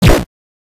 Weird sound happening often in Minecraft: "minecraft:none"